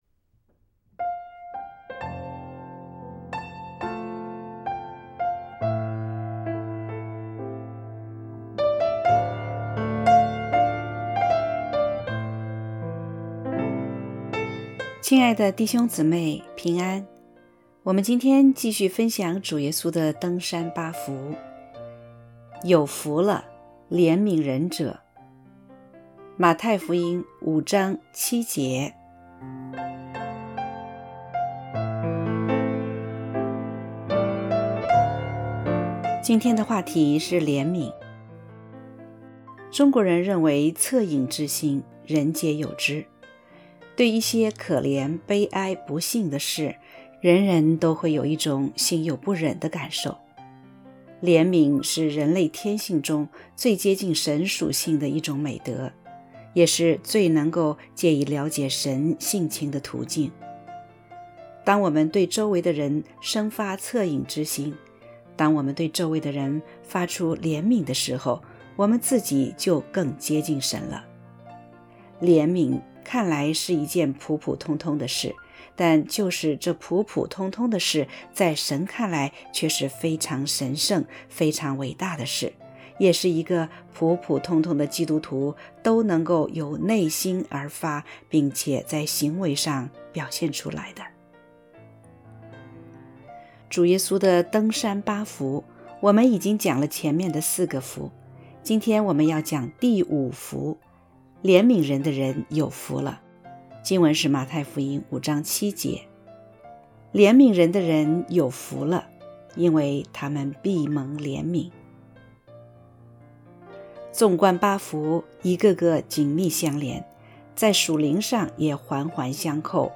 （合成）D登山八福5——怜悯人的人有福了.mp3